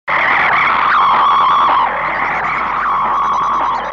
دانلود آهنگ رادیو 13 از افکت صوتی اشیاء
جلوه های صوتی
دانلود صدای رادیو 13 از ساعد نیوز با لینک مستقیم و کیفیت بالا